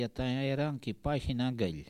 ambiance et archive
Catégorie Locution